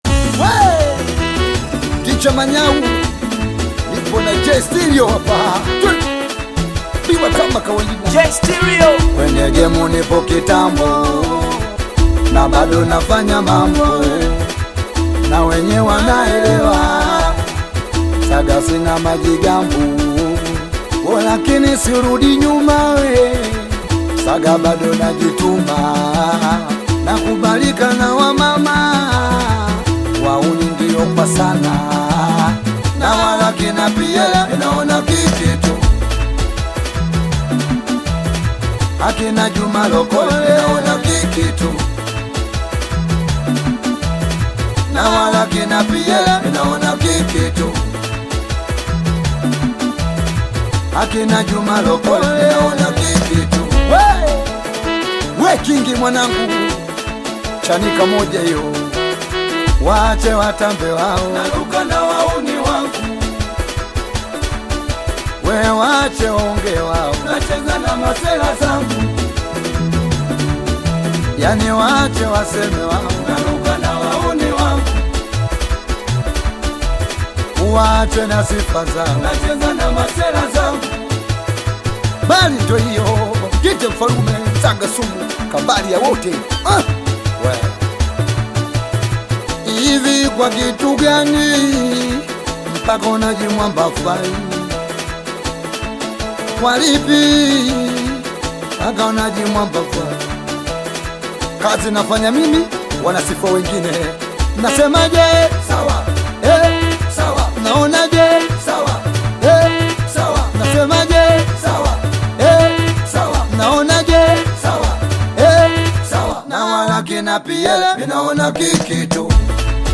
Tanzanian bongo flava singeli singer
singeli song